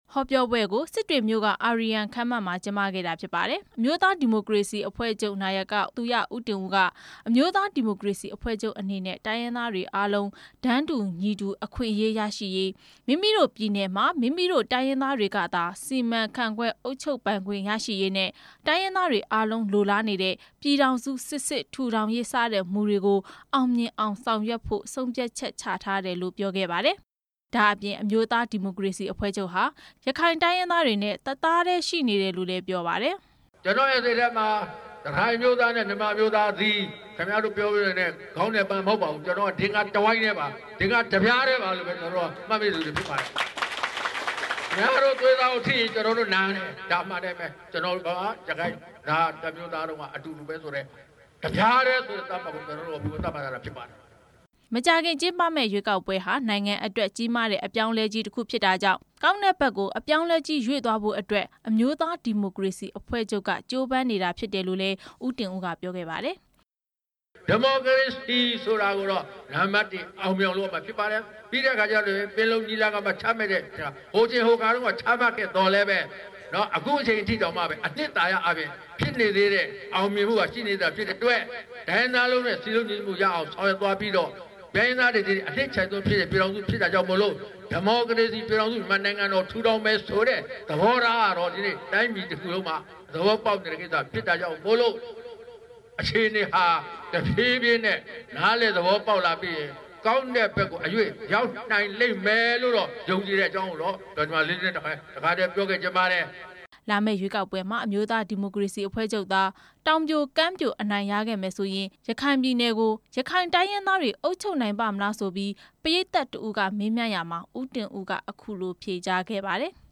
အမျိုးသားဒီမိုကရေစီအဖွဲ့ချုပ် နာယက သူရဦးတင်ဦးဟာ ရွေးကောက်ပွဲအောင်နိုင်ရေးအတွက် ဒီနေ့ စစ်တွေမြို့မှာ မဲဆွယ်စည်းရုံးဟောပြောခဲ့ပါတယ်။